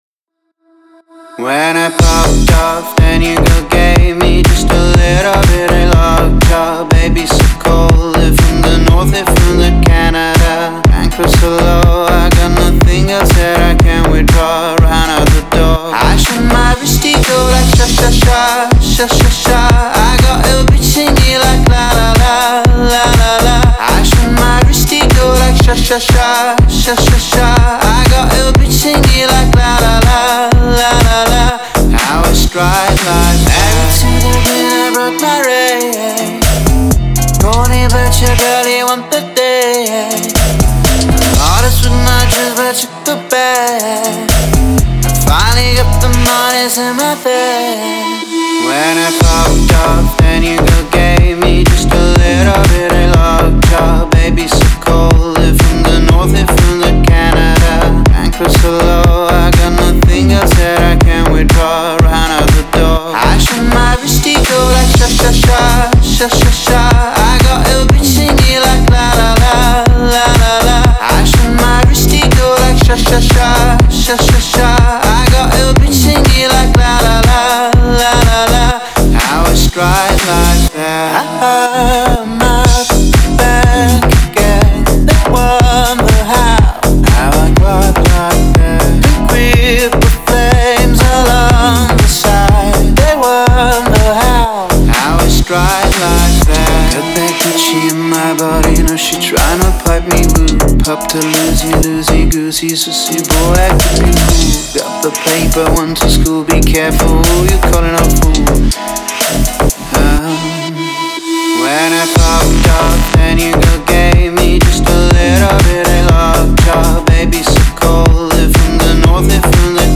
это зажигательная песня в жанре поп с элементами хип-хопа